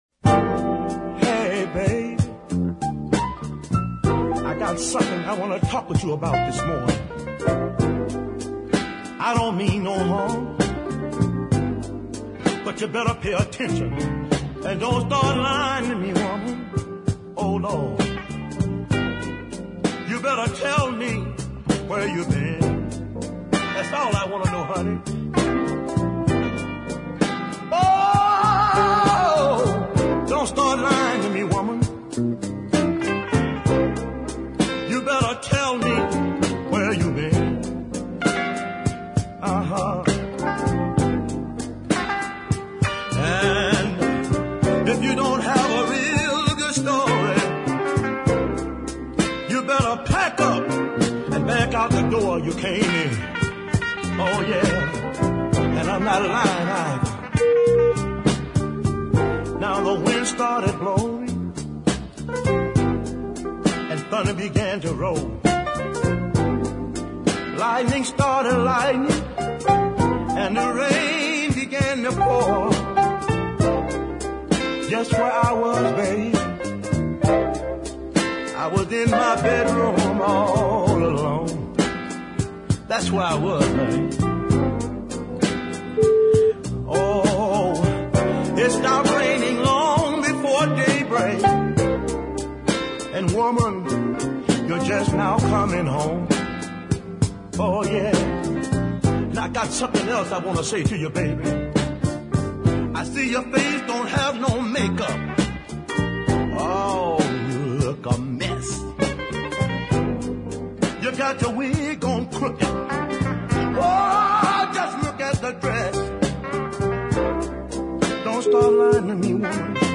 snarls away angrily